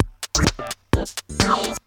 Electrohouse Loop 128 BPM (29).wav